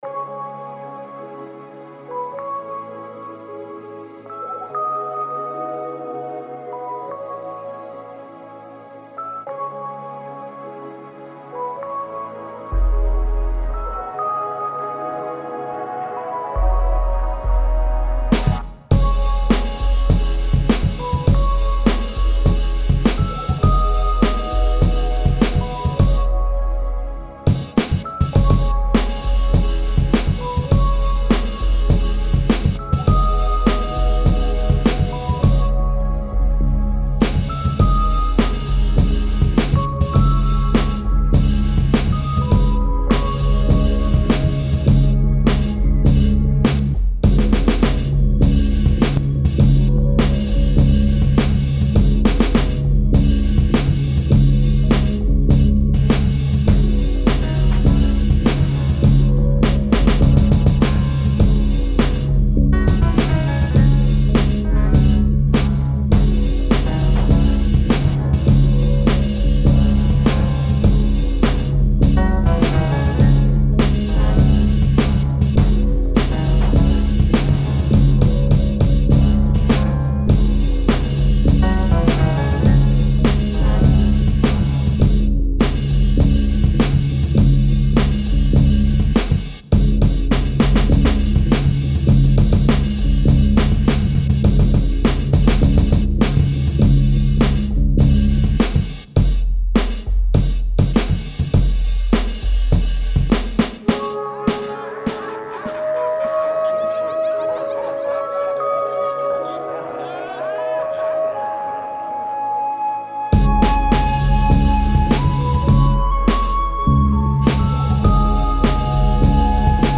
No scratching at all.